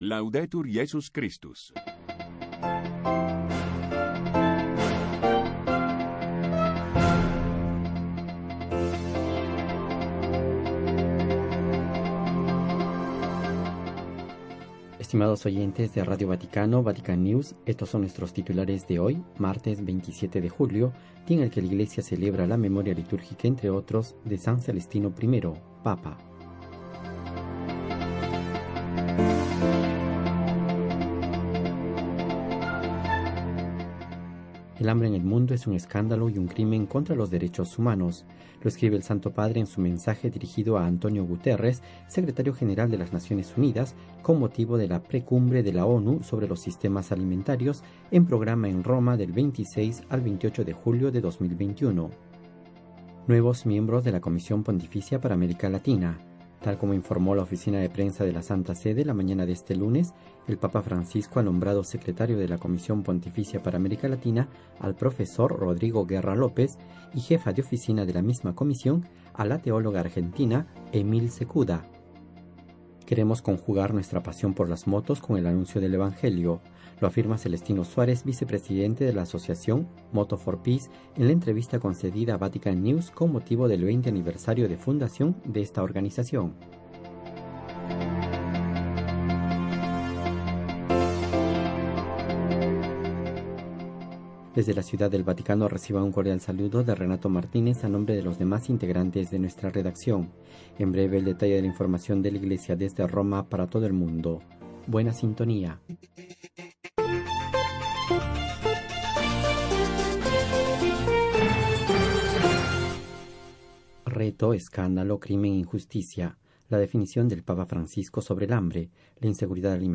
Radio Vaticana. L'informatiu en castellà de Ràdio Vaticana. Tota l'activitat del pontífex, com també totes aquelles notícies de Roma.